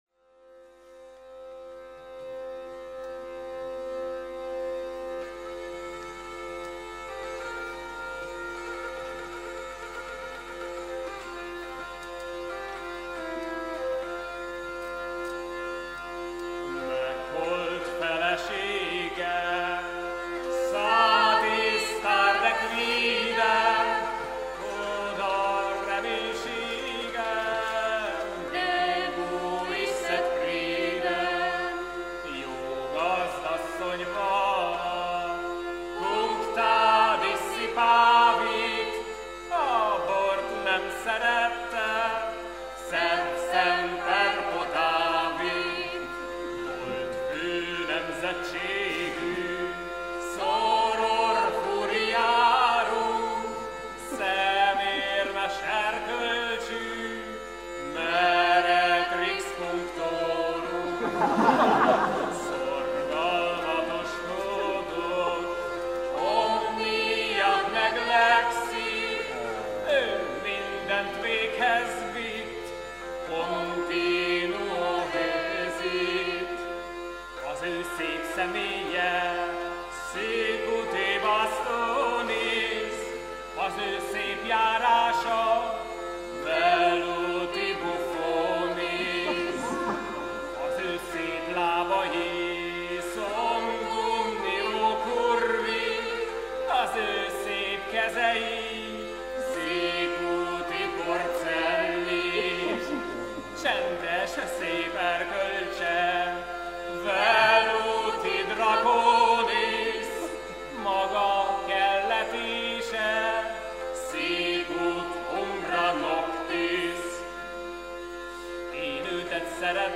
Varietas gentium – communis latinitas (International Congress of Neo-Latin Studies) – Photos and Concert Recordings
17_Megholt_Tendl_Bordalok.mp3